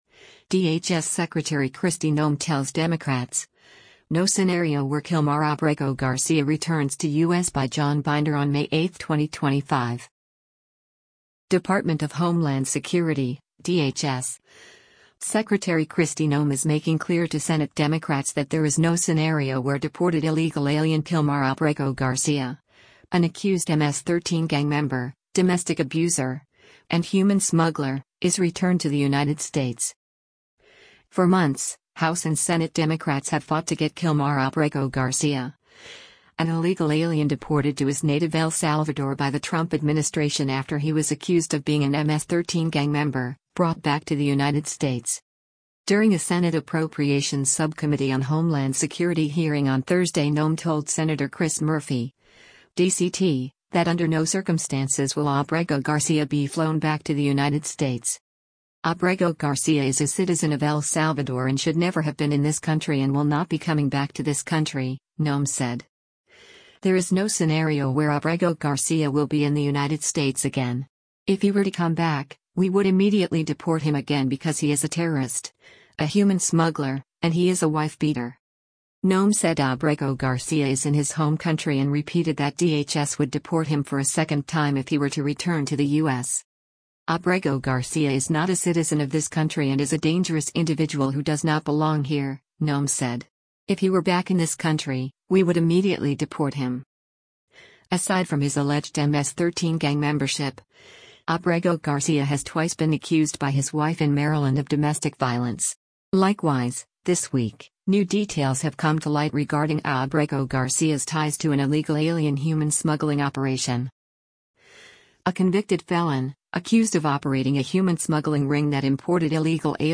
WASHINGTON, DC - MAY 8: Homeland Security Secretary Kristi Noem speaks during a Senate App
During a Senate Appropriations Subcommittee on Homeland Security hearing on Thursday Noem told Sen. Chris Murphy (D-CT) that under no circumstances will Abrego Garcia be flown back to the United States.